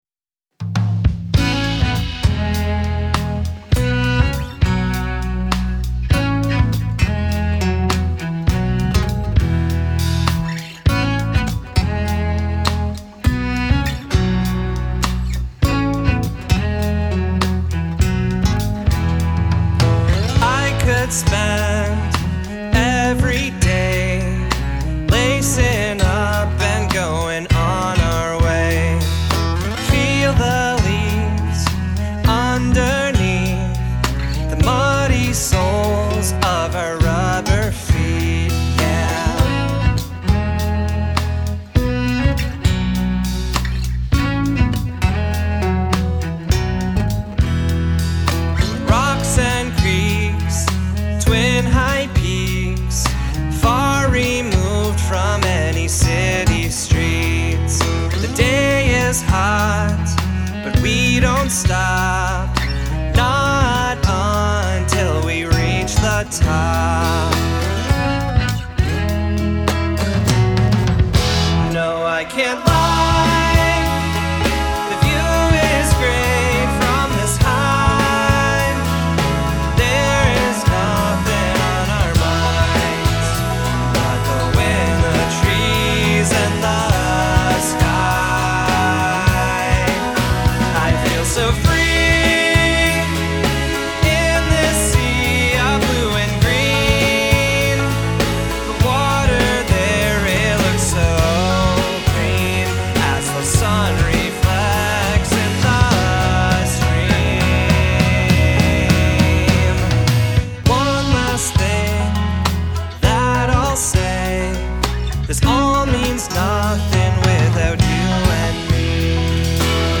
Modern Indie Jammy Alt Rock
guitar, vocal